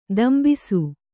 ♪ dambisu